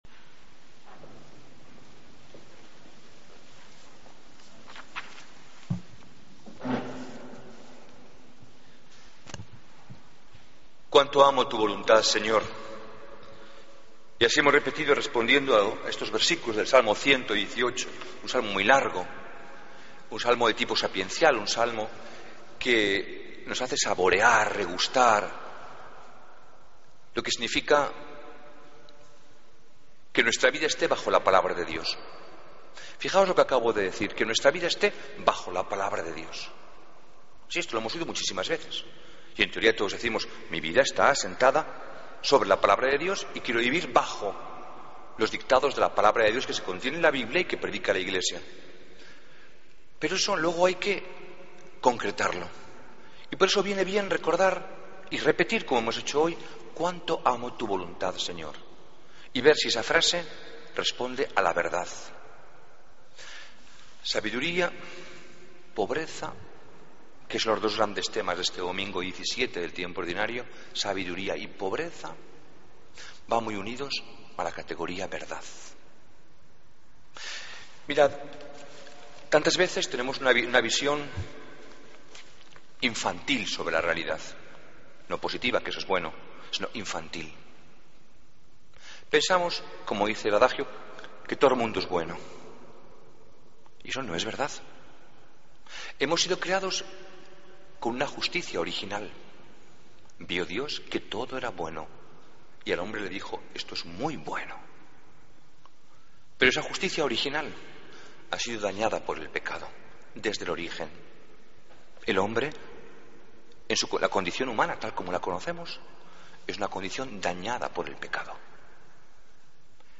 Homilía del Domingo 27 de Julio de 2014